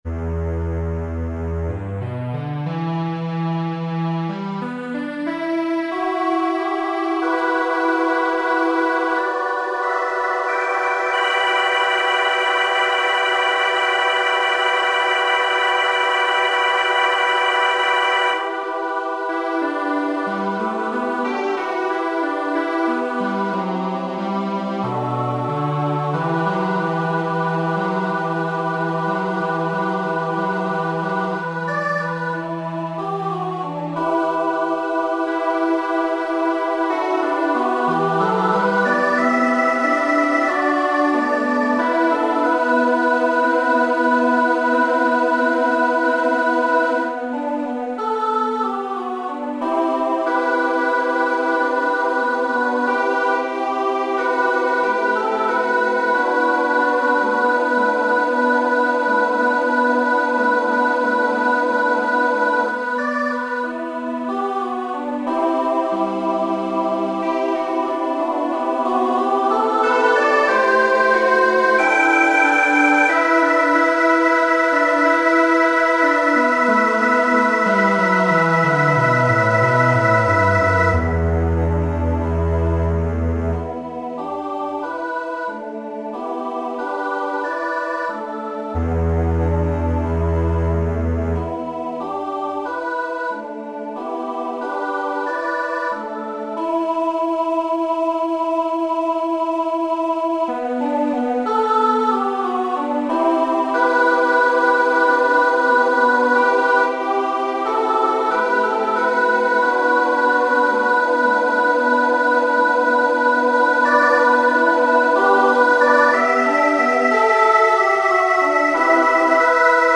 Written for treble voices and cello (with rehearsal piano)